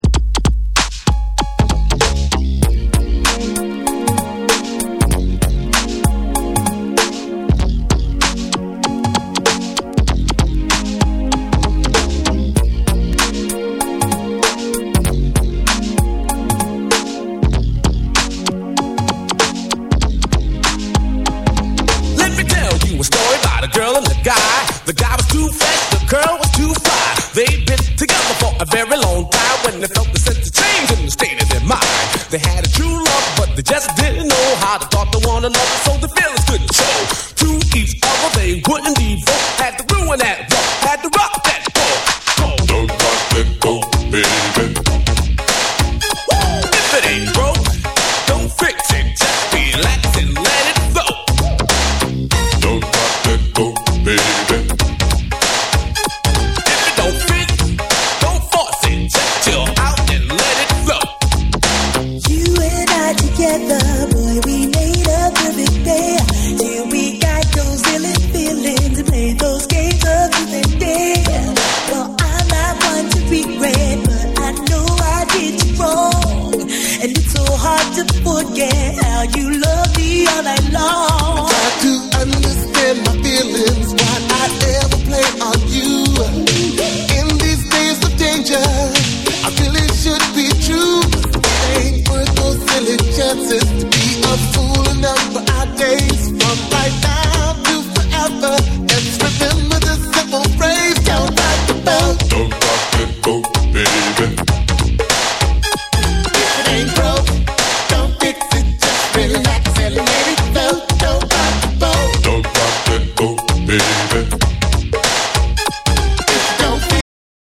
USのファンクバンド
グルーヴィーで軽快なエレクトロ・ファンク
程よいテンポ感とポップさが心地よく
洗練された80sエレクトロ・ファンクの魅力が詰まった一枚！
DANCE CLASSICS / DISCO